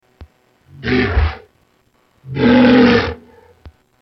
Walrus Sound 2